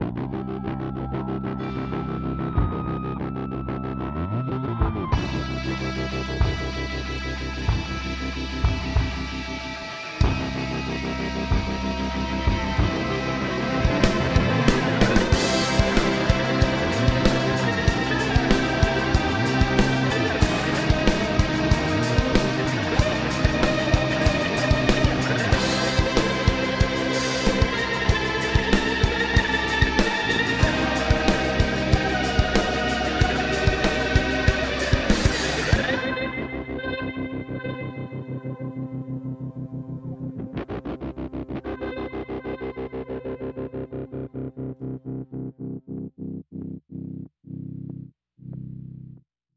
Pop Brasileiro